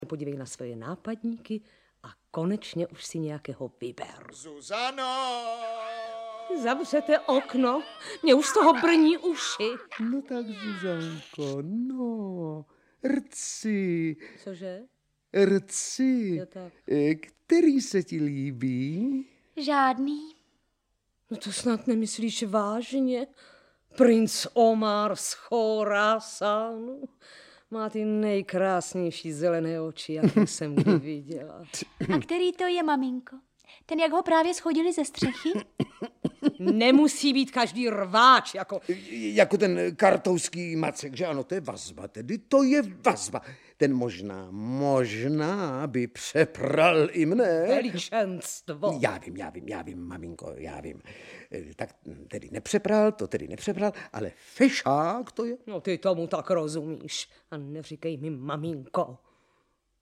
Audiobook
Read: Marek Eben